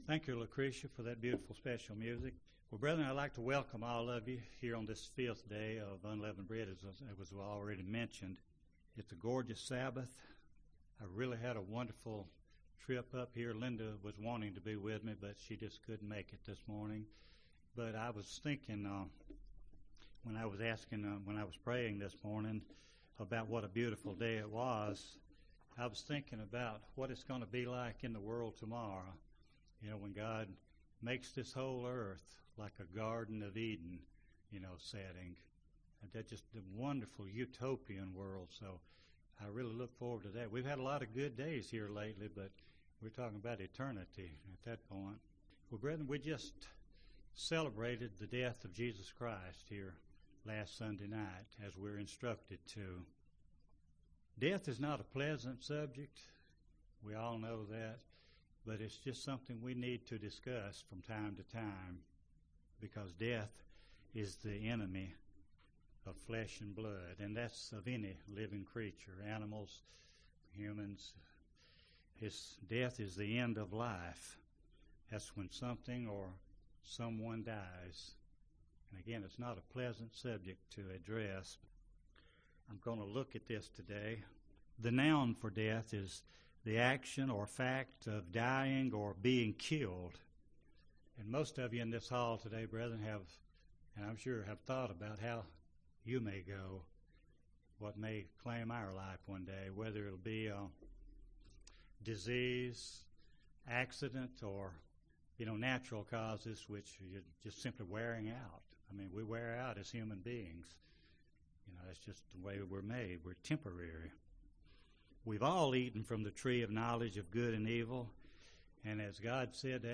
Given in Gadsden, AL